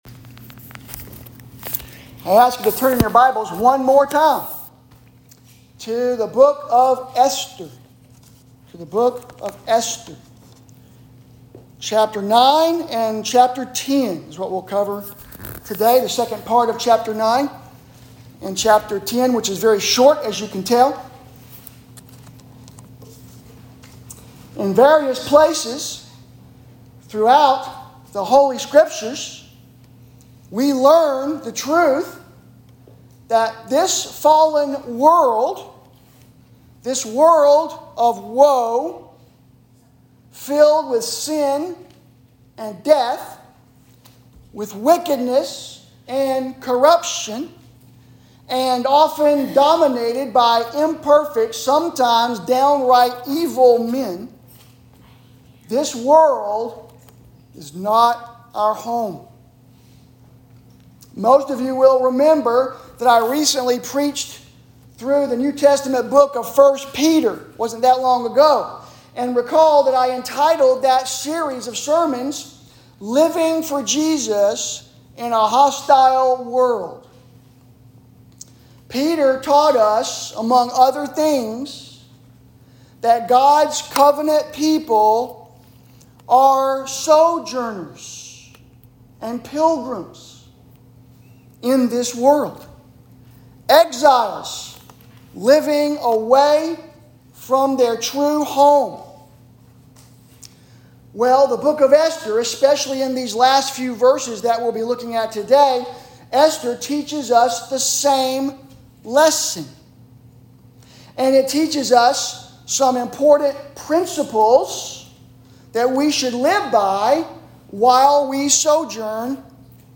Sermons – First Baptist Church